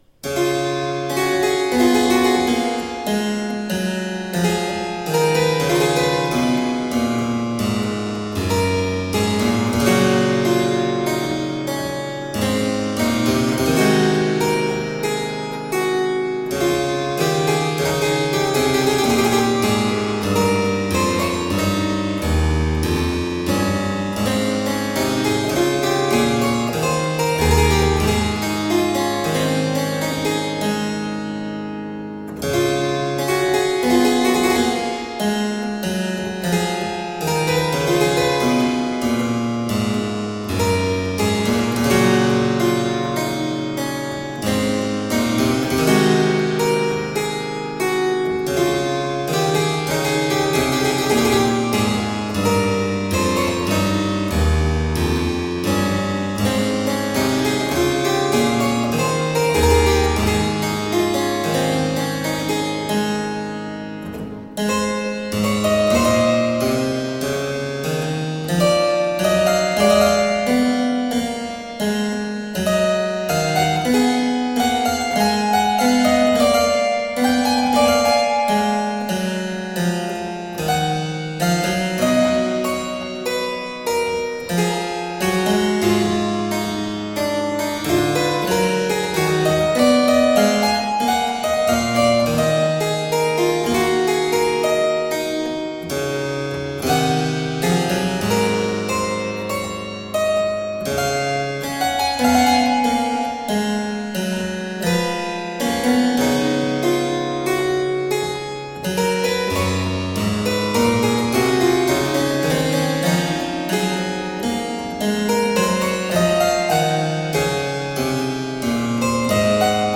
Bach on the harpsichord - poetic and expressive.